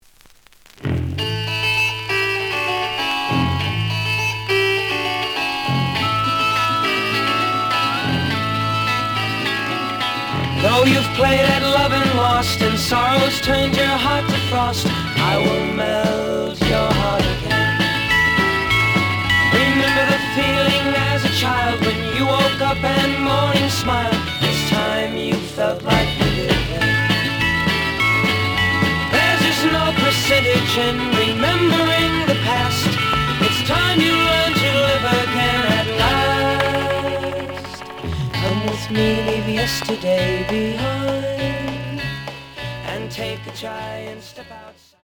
The audio sample is recorded from the actual item.
●Genre: Rock / Pop
Noticeable cloudy on both sides.